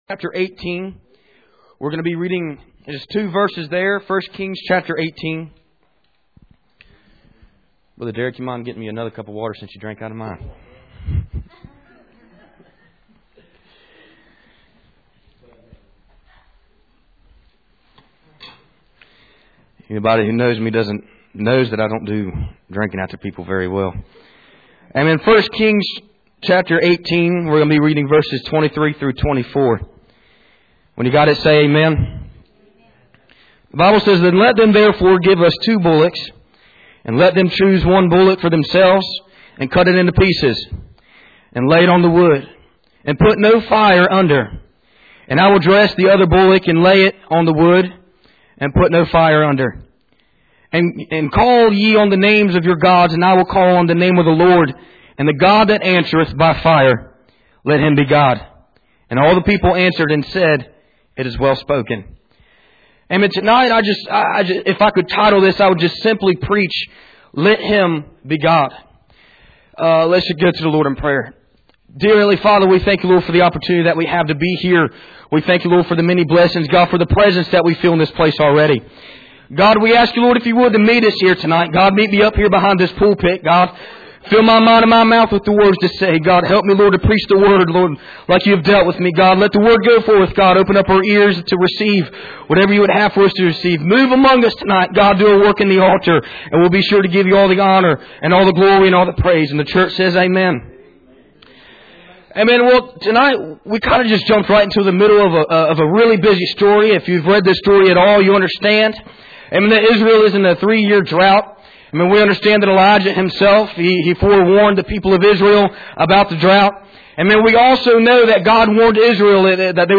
1 Kings 18:23-24 Service Type: Sunday Evening %todo_render% « No fruit